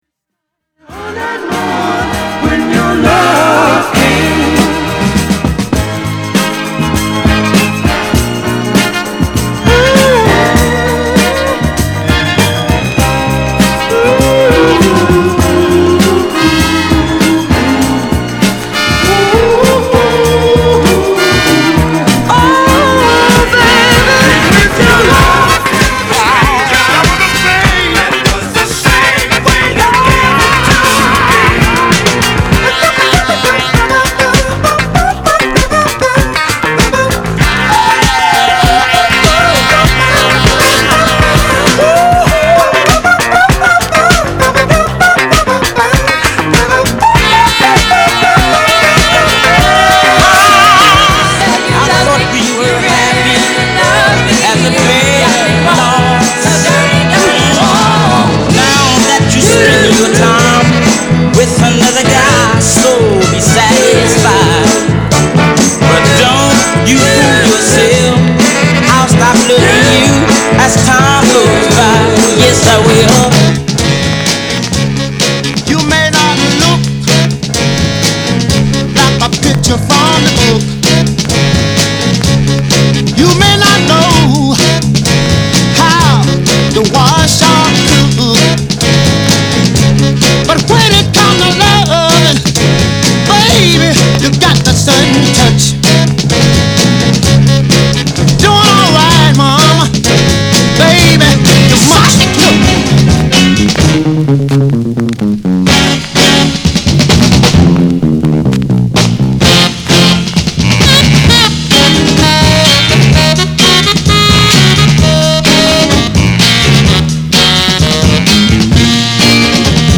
ブルース